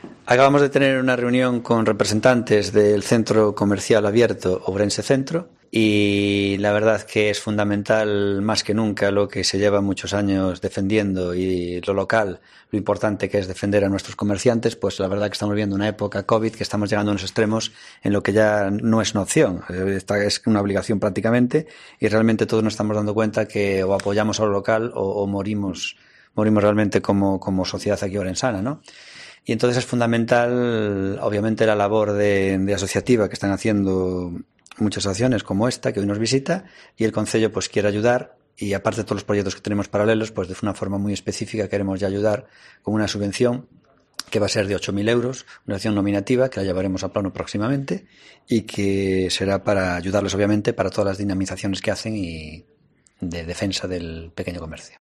Declaraciones de Gonzalo Pérez Jácome tras la reunión con los representantes del CCA Ourense Centro